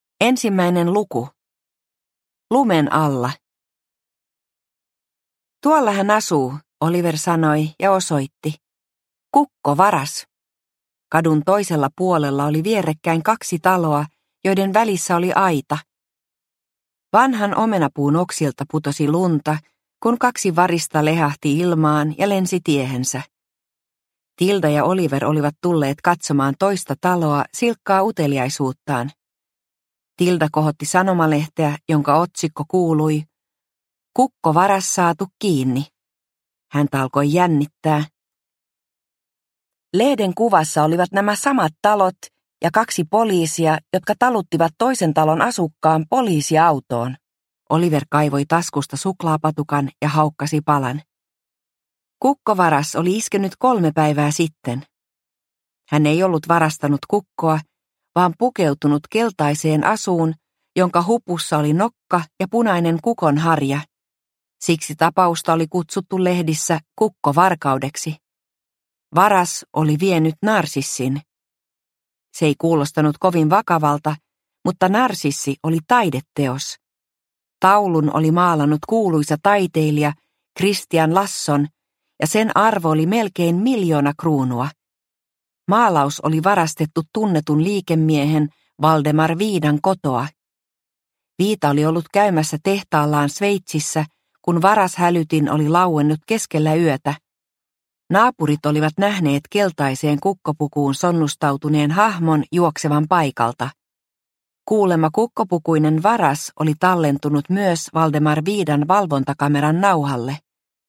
Operaatio Narsissi – Ljudbok – Laddas ner